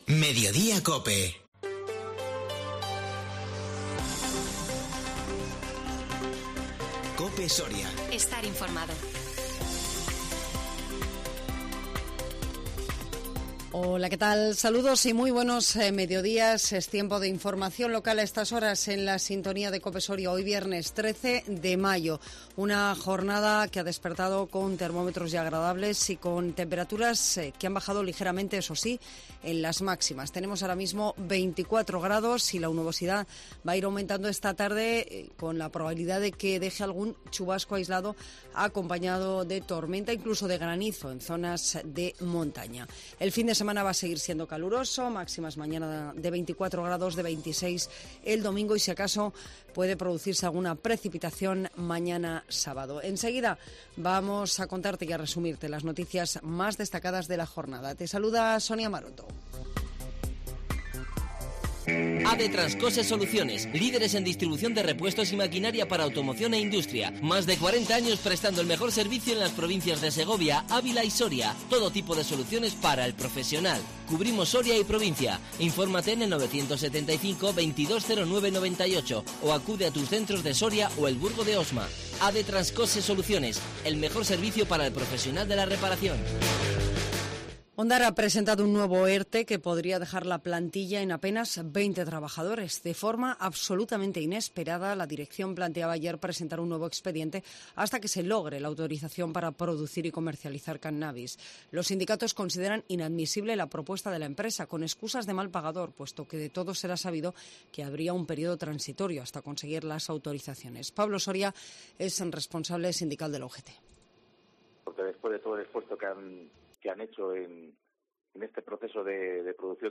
INFORMATIVO MEDIODÍA COPE SORIA 13 MAYO 2022